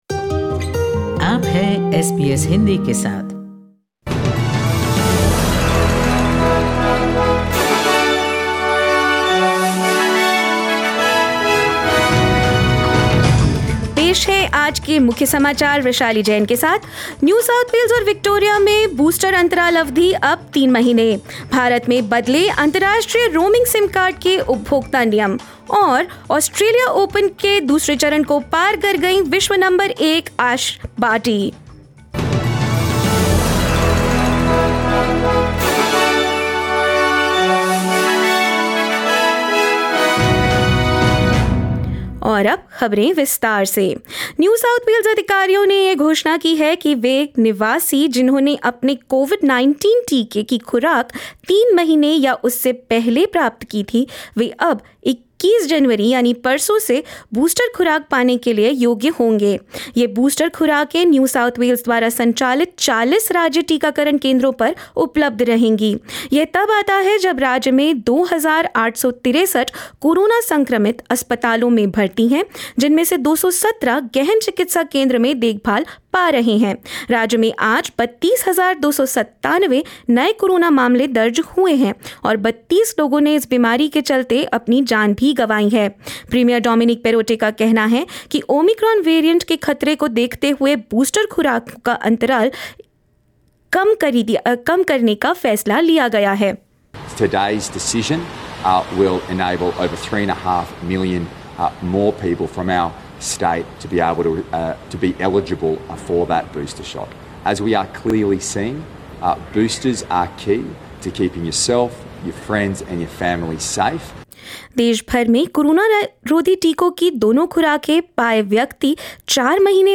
In this latest SBS Hindi bulletin: The Interval between the second and third doses of COVID-19 vaccines will be reduced to three months in Victoria, New South Wales and South Australia; Ash Barty qualifies for the third round of Australian Open on First Nations Day and more.